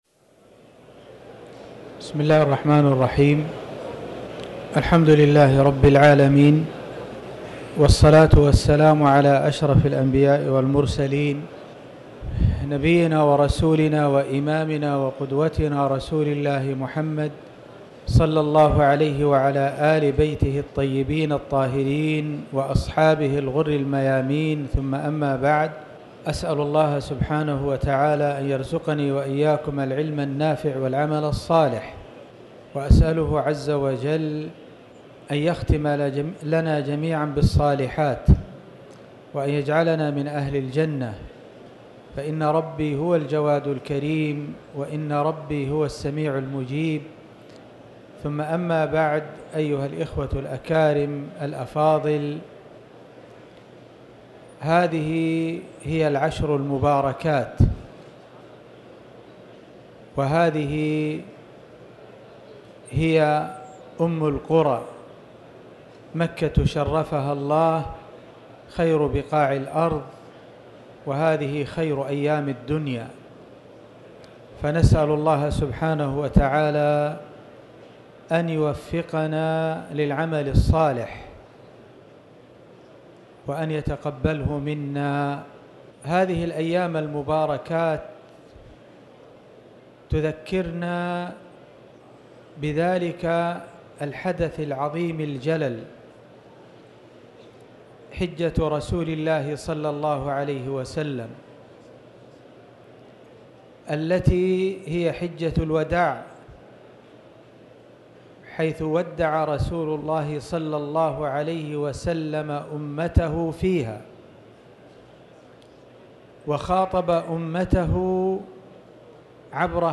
محاضرة حجة الوداع
المكان: المسجد الحرام